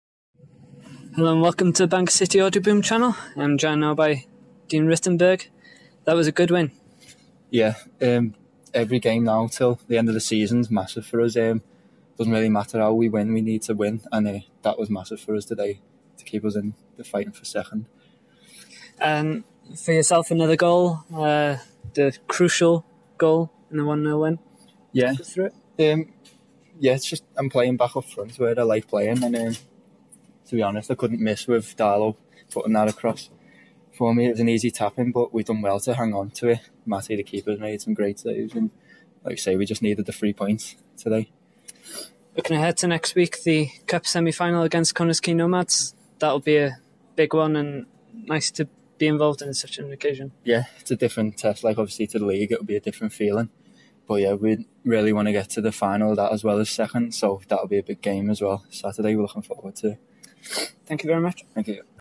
Citizens Interview